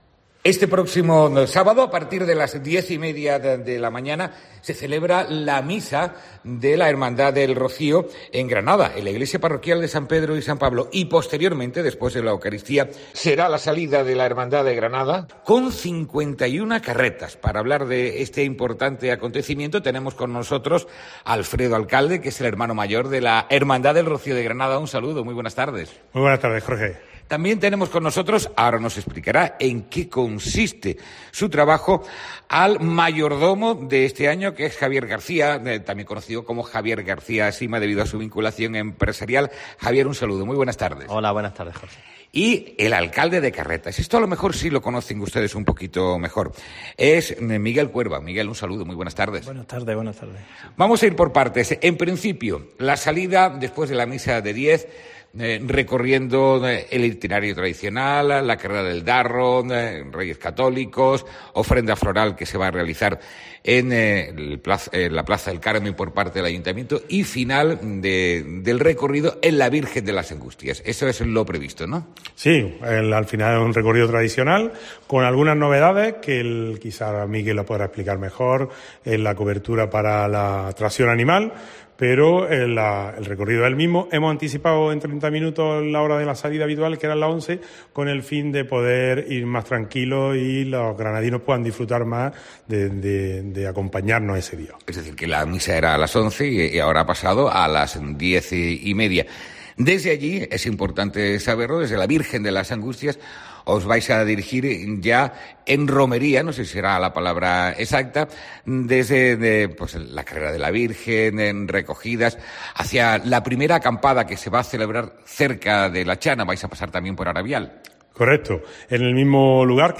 ENTREVISTA|| Este sábado parte la Hermandad del Rocío de Granada a la aldea almonteña